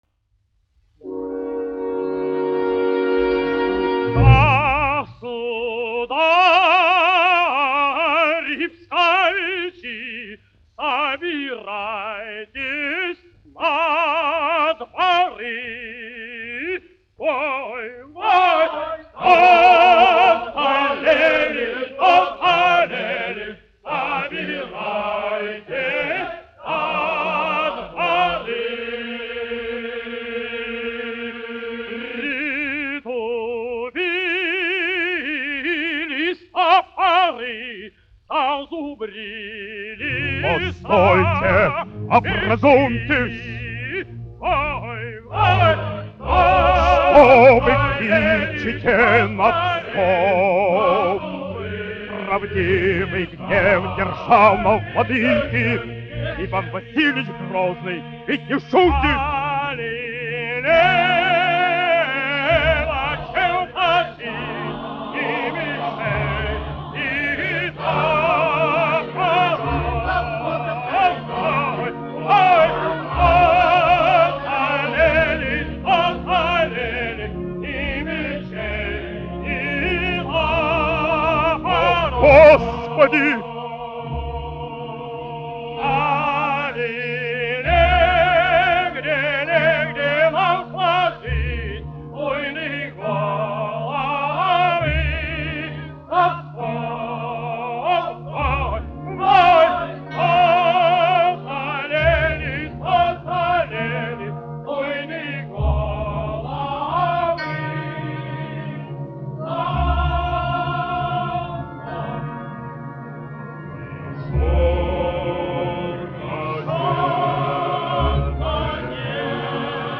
Большой театр СССР. Оркестр, izpildītājs
Большой театр СССР. Хор, izpildītājs
1 skpl. : analogs, 78 apgr/min, mono ; 25 cm
Operas--Fragmenti
Skaņuplate